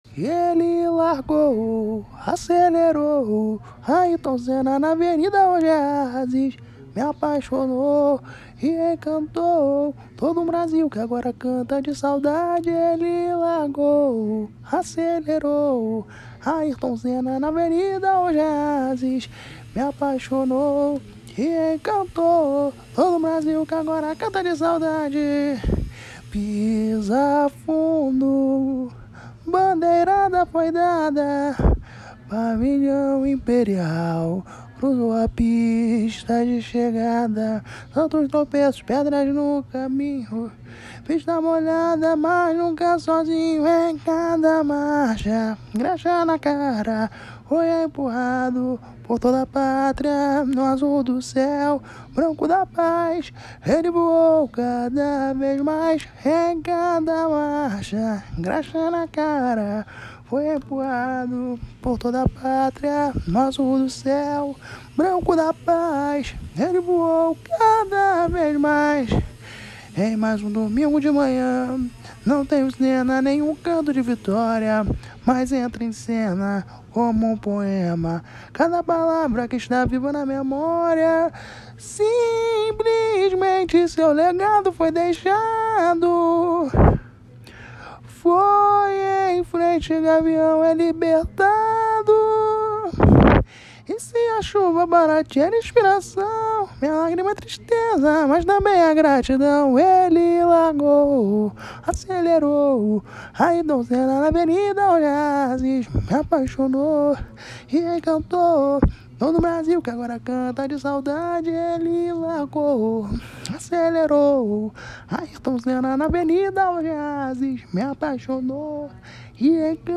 Samba  03